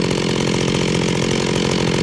SAWIDLE1.mp3